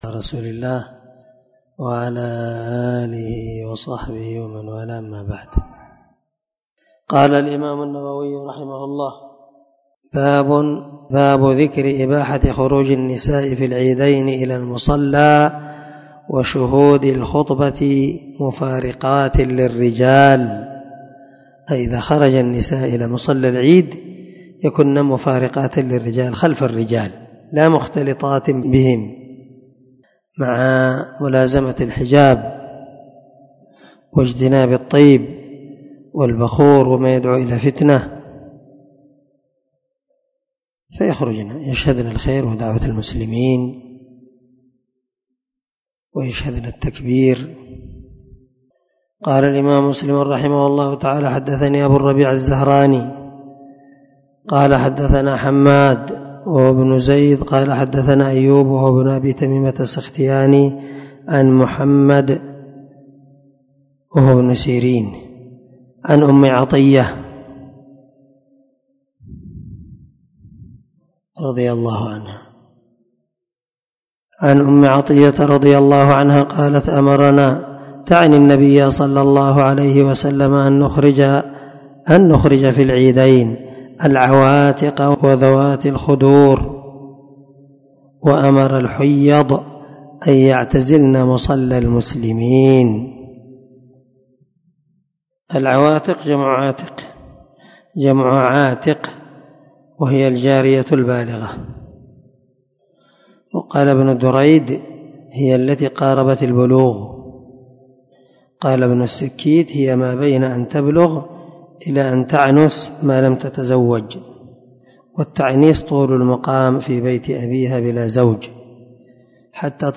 540الدرس 3 شرح كتاب صلاة العيدين حديث رقم (890)صحيح مسلم